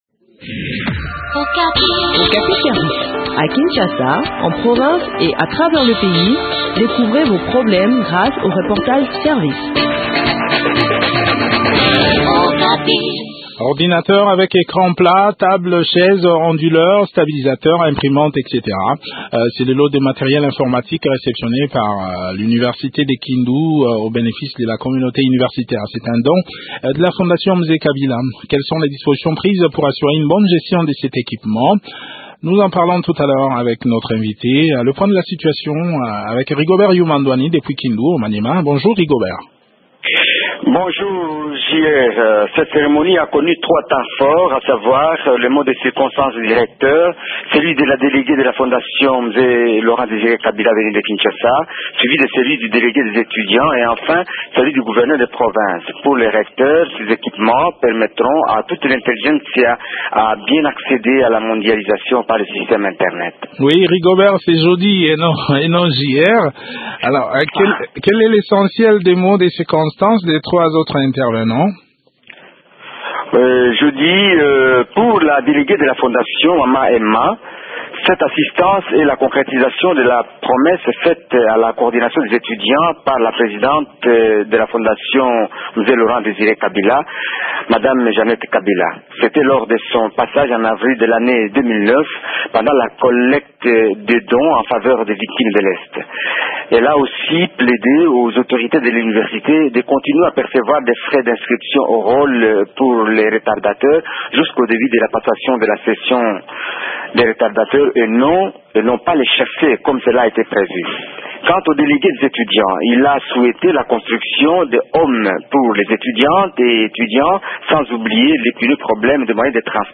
Le point sur l’utilisation de ces matériels dans cet entretien